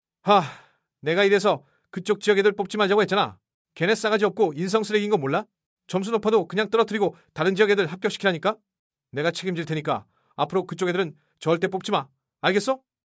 들려드린 목소리는 모두 AI로 복제된